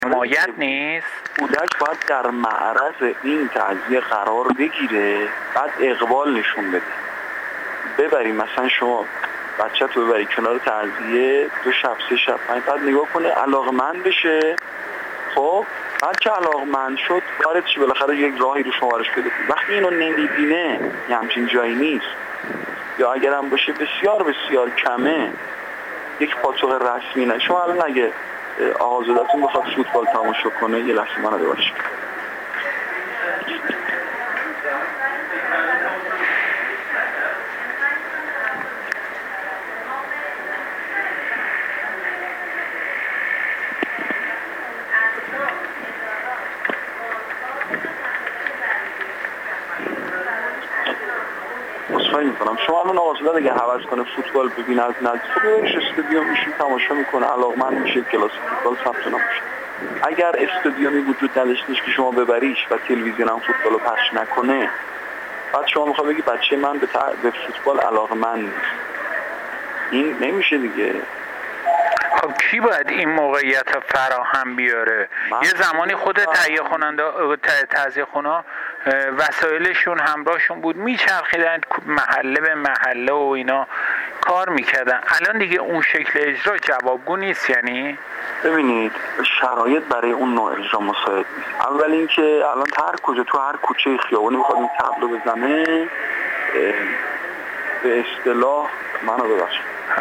گفت‌و‌گو با ایکنا